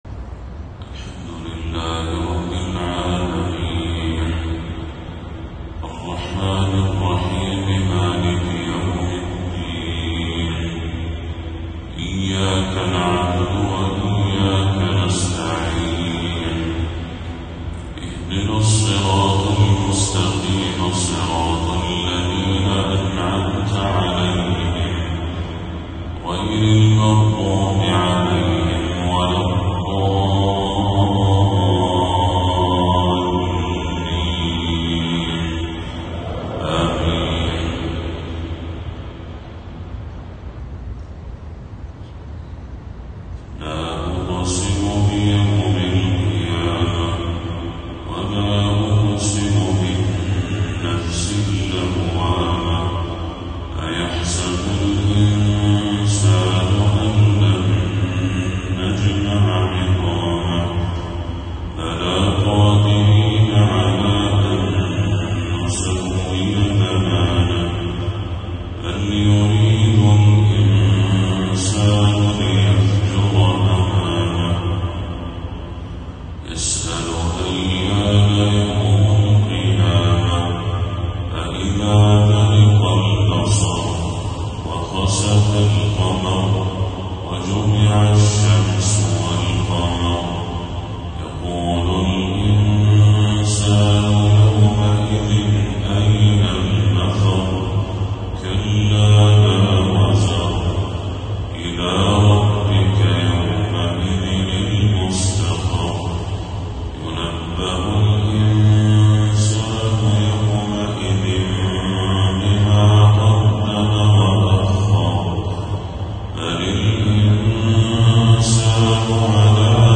فجر 12 صفر 1446هـ